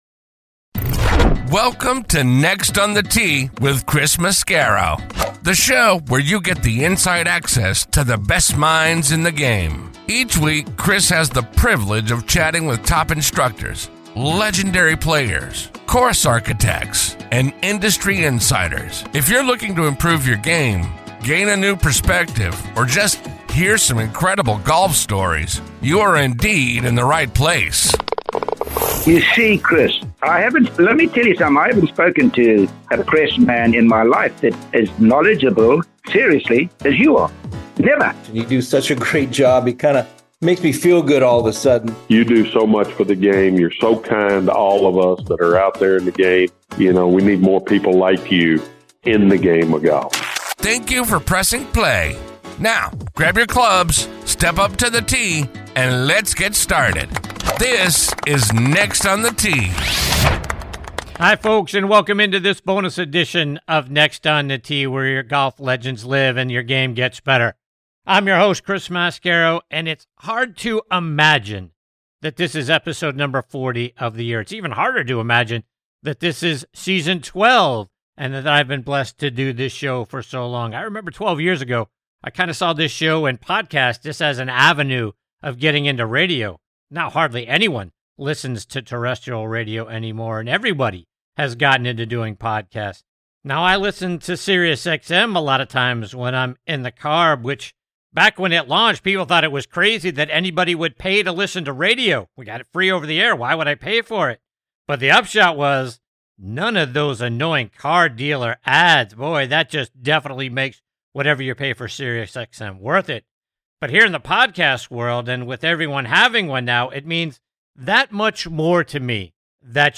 On this bonus edition of Next on the Tee, I’m thrilled to be joined by two outstanding guests who are making a major impact on the game.